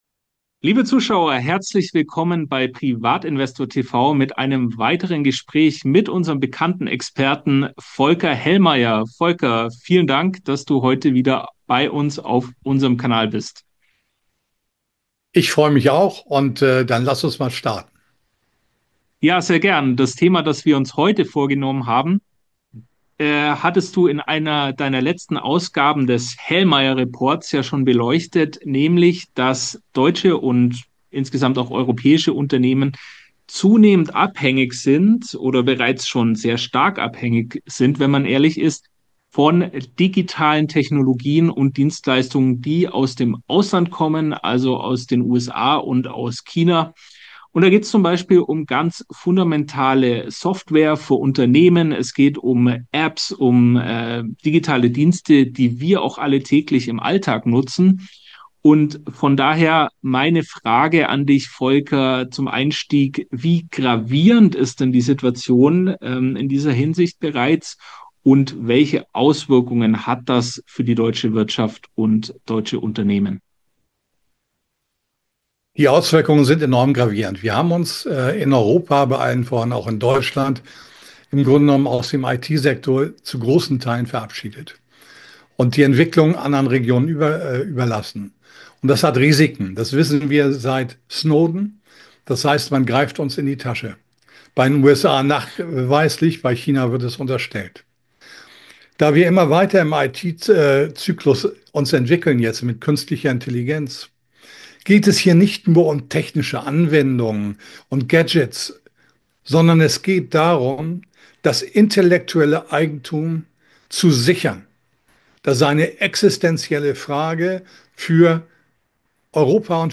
Ein wichtiges Gespräch über digitale Souveränität, verlorene Talente und was jetzt politisch passieren muss.